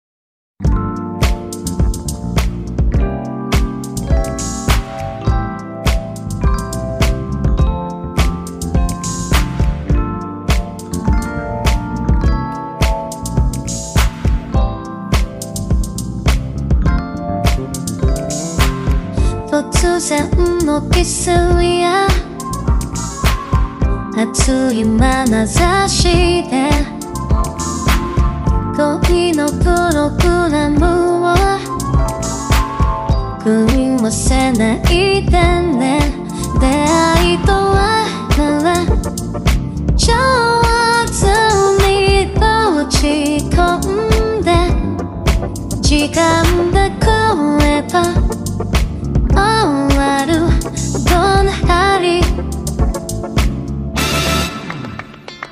以上来自新低模训练下的推理模型，这里也无偿分享给大家尝试训练好的推理模型供大家研究学习。